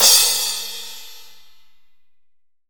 Index of /90_sSampleCDs/AKAI S6000 CD-ROM - Volume 3/Crash_Cymbal2/SHORT_DECAY_CYMBAL